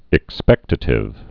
(ĭk-spĕktə-tĭv)